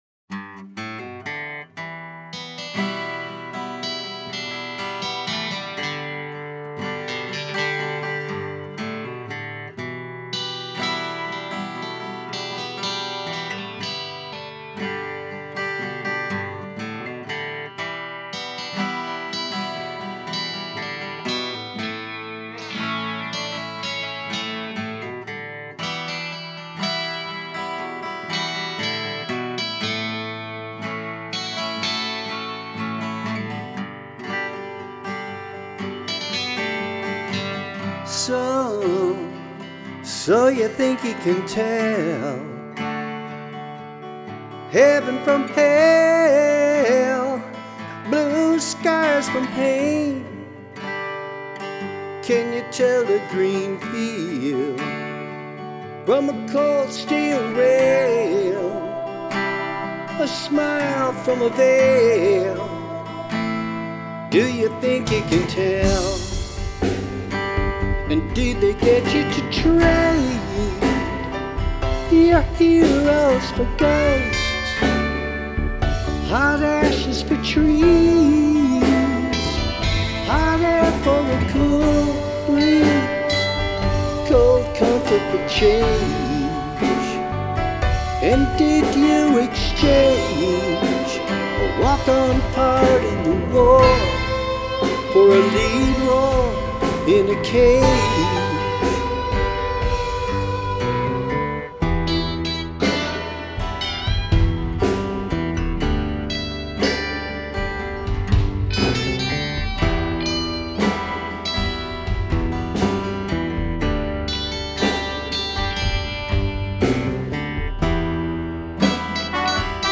Vocals, Acoustic Lead & Rhythm Guitars
ALL other instruments and audio FX